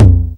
prcTTE44003tom.wav